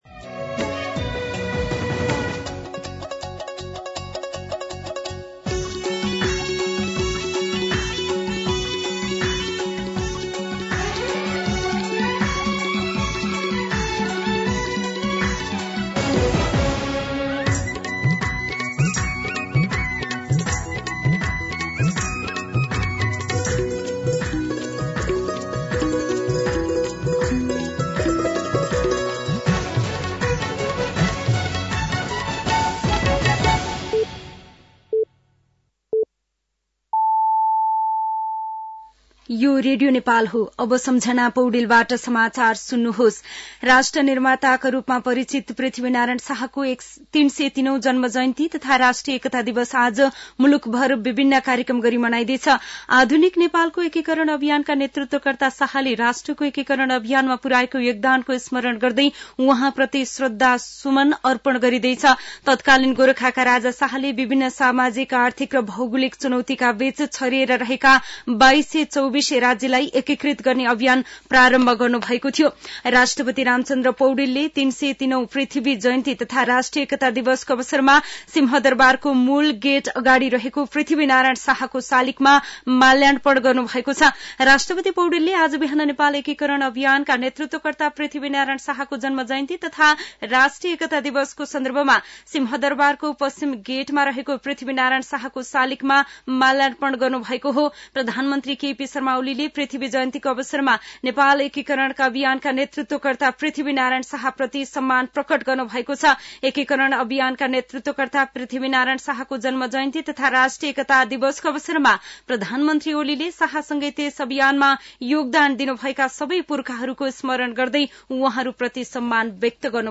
दिउँसो ४ बजेको नेपाली समाचार : २८ पुष , २०८१
4-pm-Nepali-News-9-27.mp3